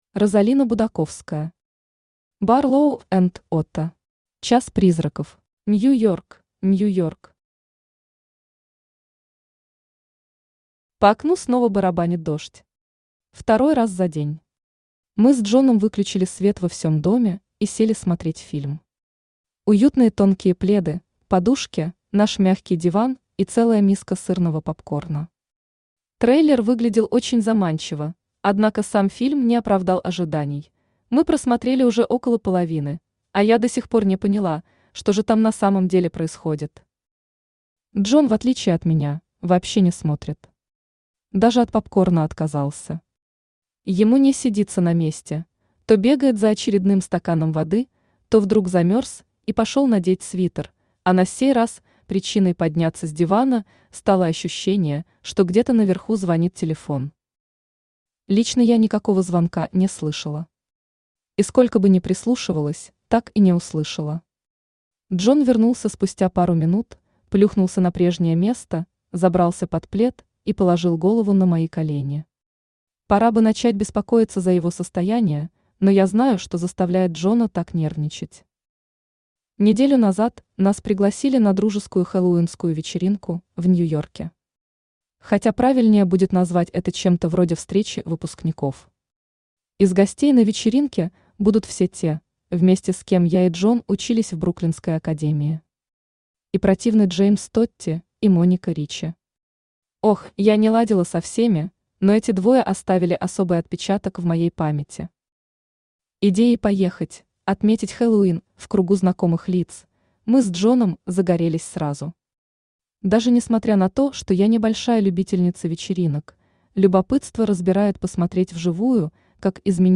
Аудиокнига Barlow&Otto.
Час призраков Автор Розалина Будаковская Читает аудиокнигу Авточтец ЛитРес.